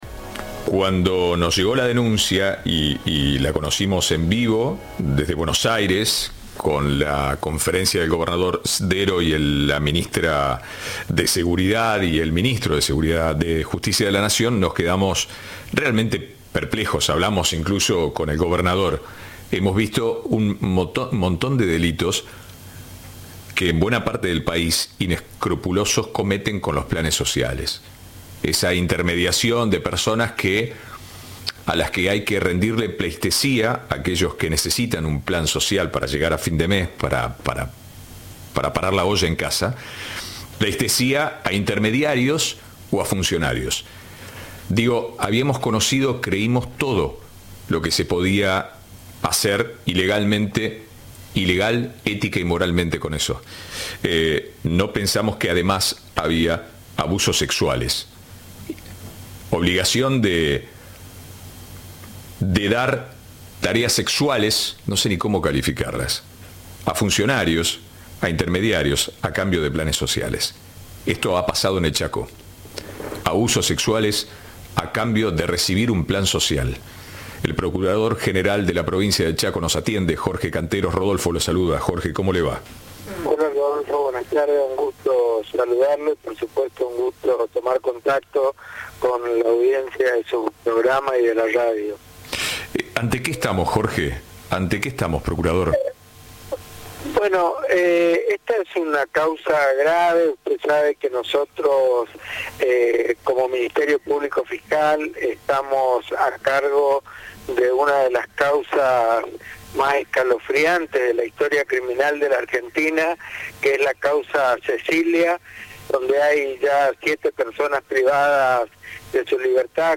El procurador general de la provincia de Chaco, Jorge Canteros, habló con Cadena 3 y explicó que hay dos denuncias y que las víctimas se encuentran con contención al activarse todos los protocolos.